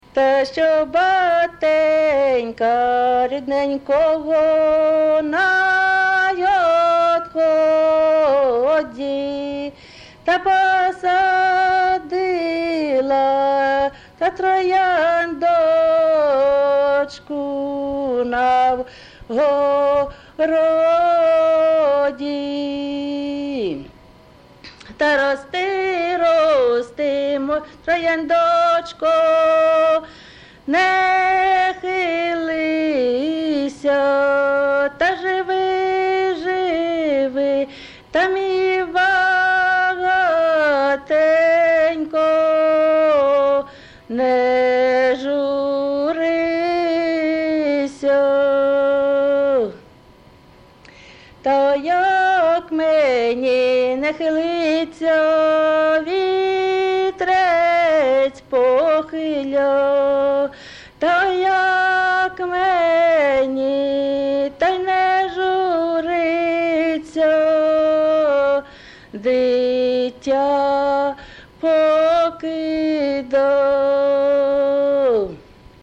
ЖанрВесільні
МотивЖурба, туга
Місце записус. Яблунівка, Костянтинівський (Краматорський) район, Донецька обл., Україна, Слобожанщина